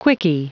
Prononciation du mot quickie en anglais (fichier audio)
Prononciation du mot : quickie